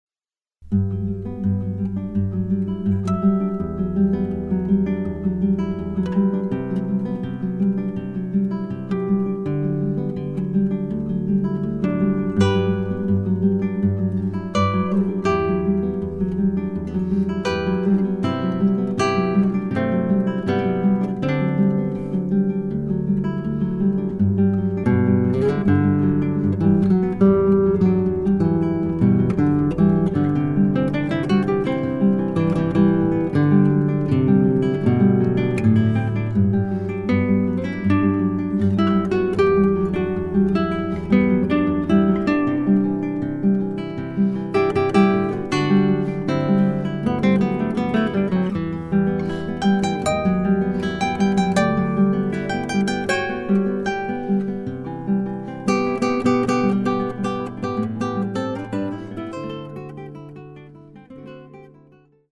DÚO DE GUITARRAS